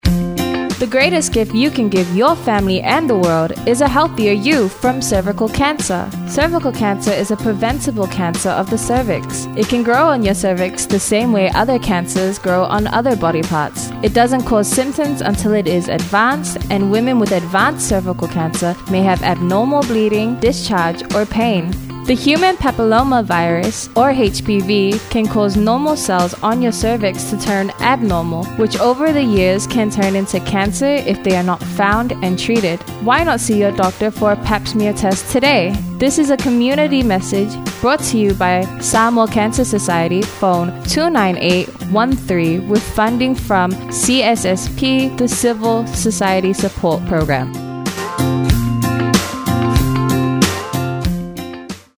scscervicalcancerradioad_eng.mp3